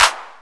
808-Clap04.wav